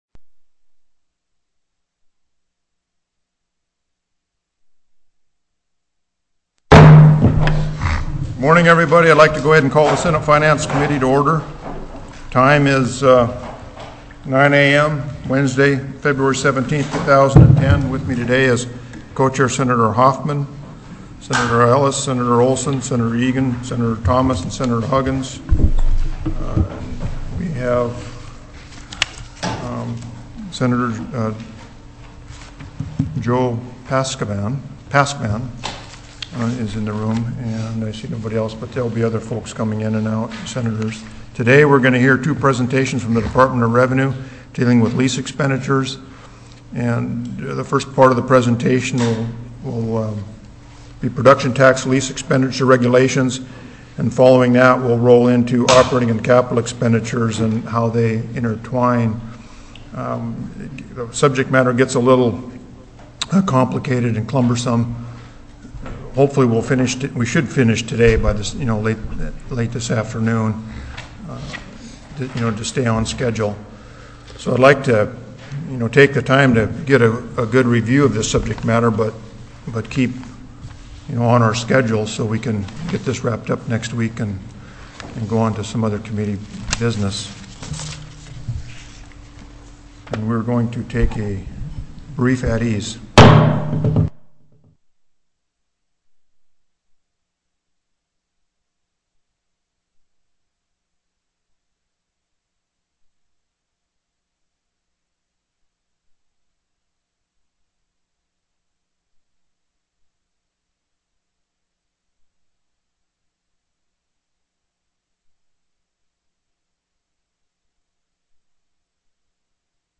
Co-Chair Stedman called the Senate Finance Committee meeting to order at 9:00 a.m.